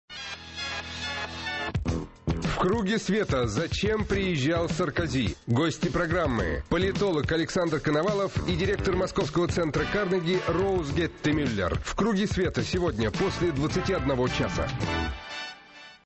программа Светланы Сорокиной
на радио «Эхо Москвы»
Аудио: анонс –